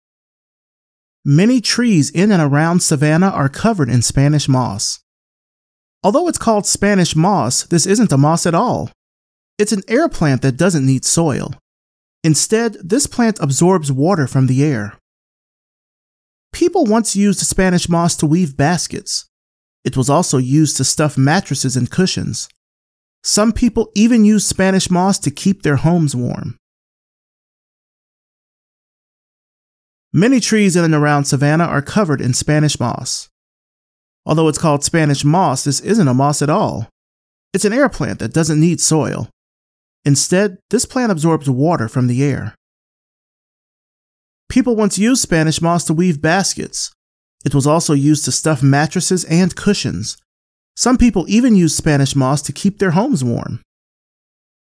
Your Friendly, Neighborhood, Guy Next Door Narrator
African-American, U.S. Southern, French
Young Adult